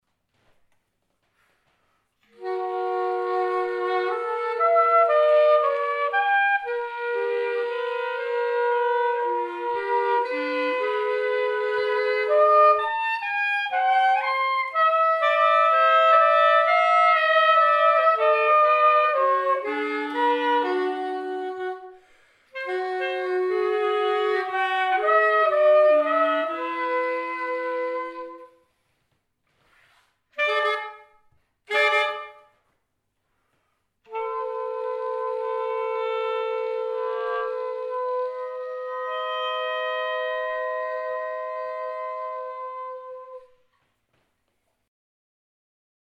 Composition: